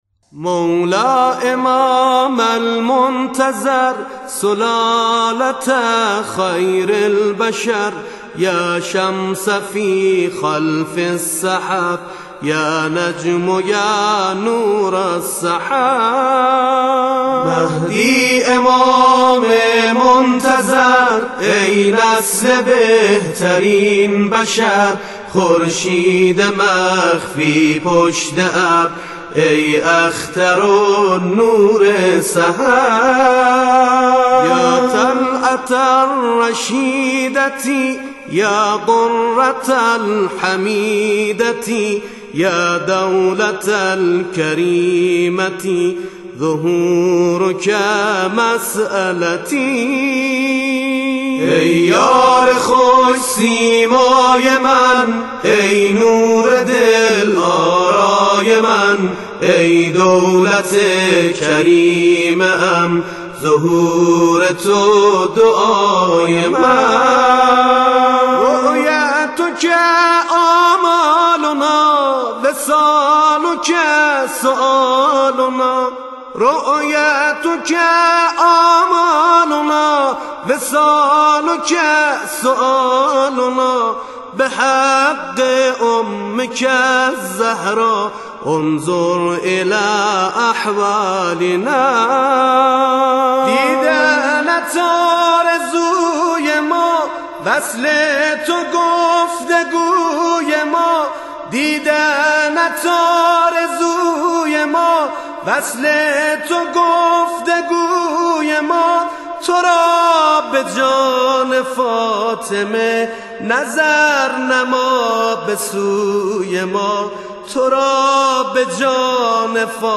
همخوانی مهدوی